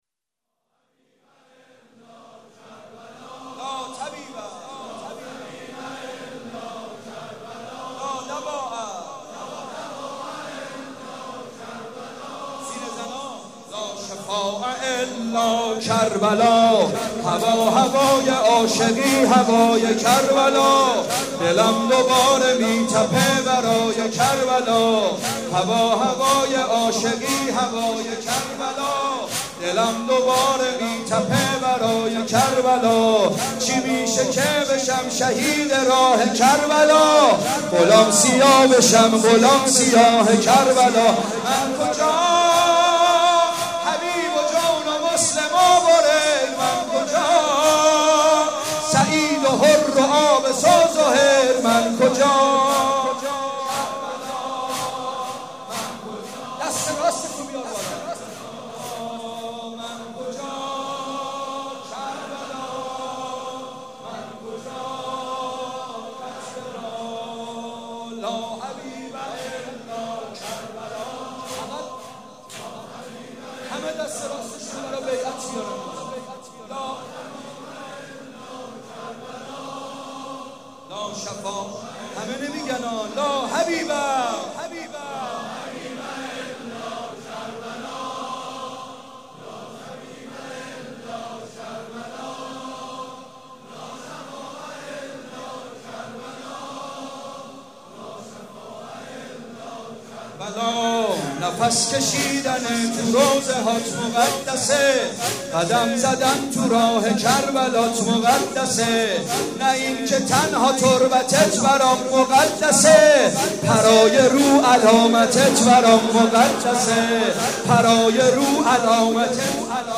مداحی شب سوم صفر/هیئت ریحانه الحسین(س)
روضه